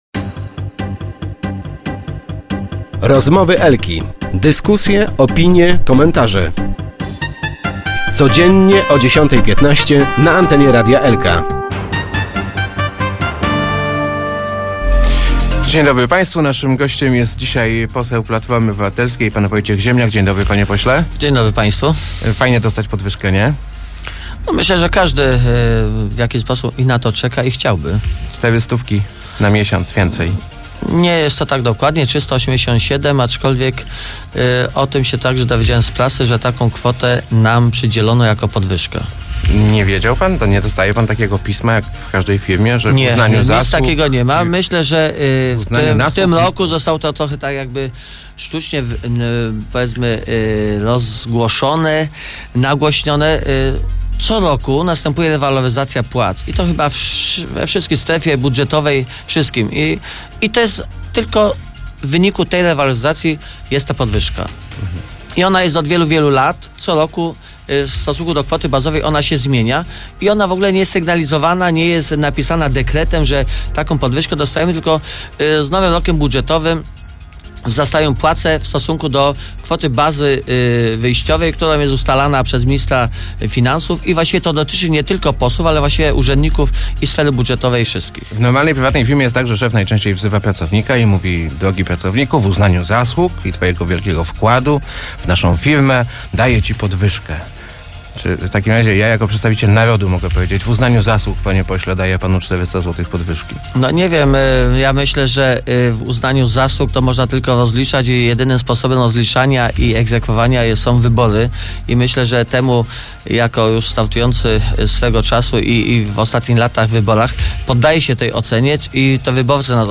thumb_poswziemniak.jpgLeszno. Nie mieliśmy żadnego wpływu na podwyżki naszych pensji – tłumaczył w „Rozmowach Elki” poseł PO Wojciech Ziemniak.